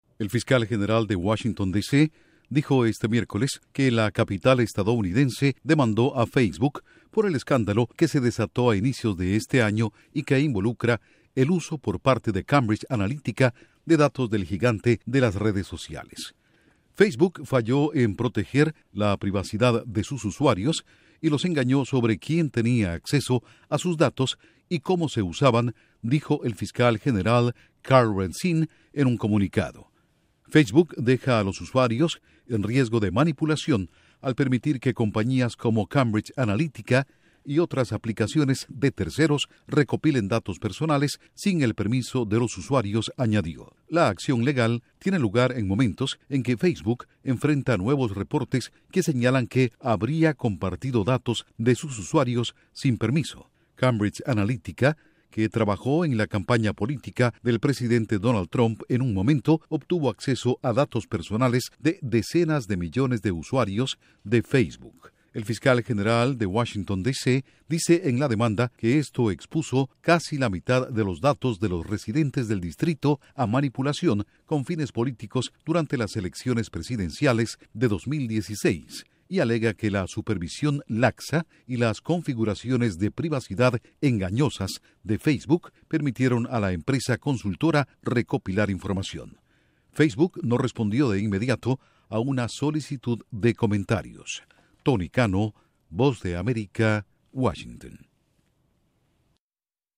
Fiscal general de Washington DC demanda a Facebook por Cambridge Analytica: Washington Post. Informa desde la Voz de América en Washington